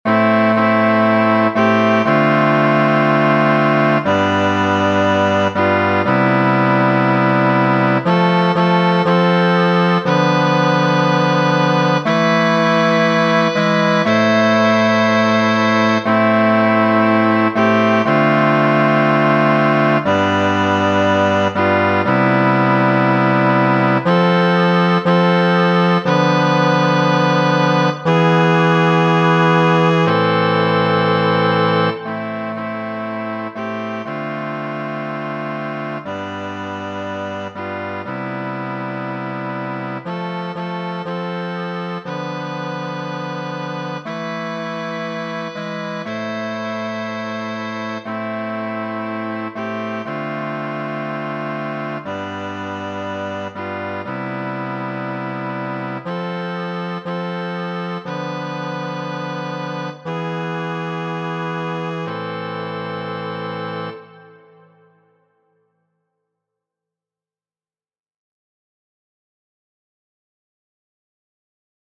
Nenio perturbu vin, kanto komponita de franca muzikisto Jacques Berthier sur poemo de hispana monaĥino Teresin' de Ávila. Aŭskultu la version por voĉoj (4'47").